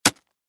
Звуки топора
На этой странице собраны разнообразные звуки, связанные с работой топора: от мощных ударов по дереву до звонкого отскока лезвия.
звук ледяного топора раскалывающего лед